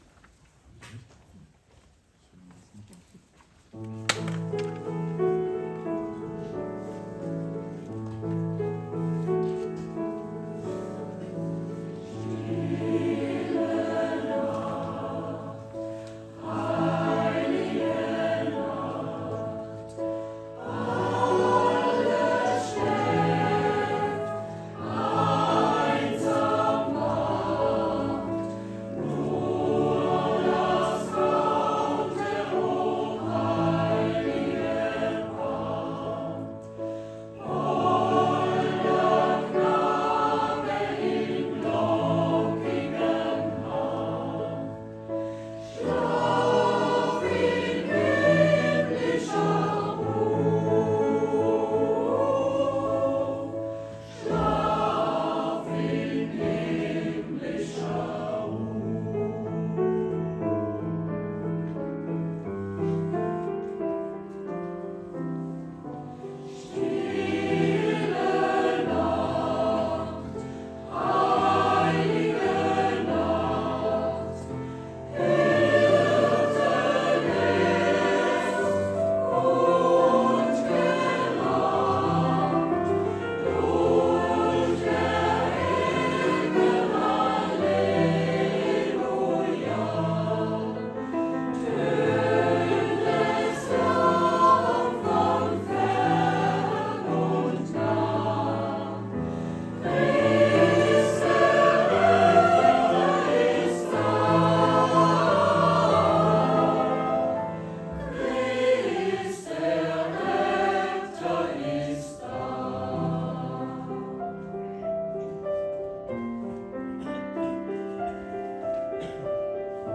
Dezember 2024 › Der Chor GV Grunbach e.V.
Weihnachtliche Soirèe am 3. Advent.